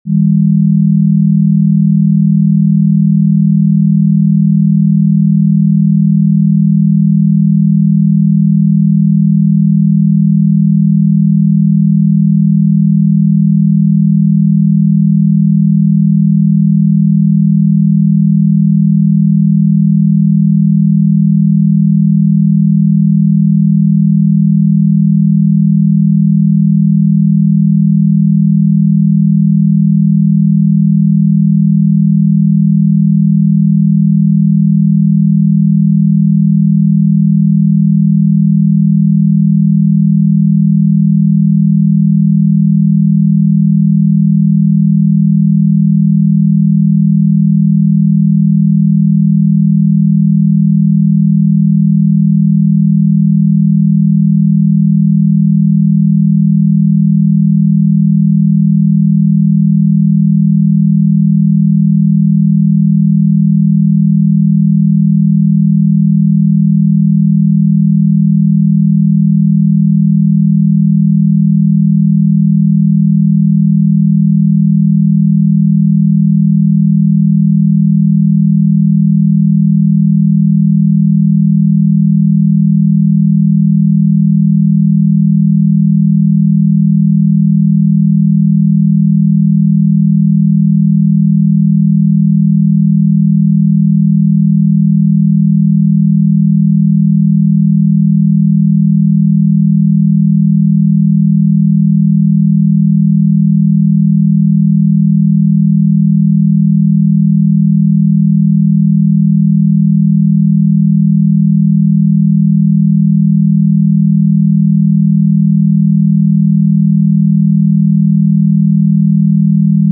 Unlock Your Brain’s Potential with 40Hz Binaural Beats Experience the power of 40Hz binaural beats, known to boost memory, enhance focus, and promote mental clarity.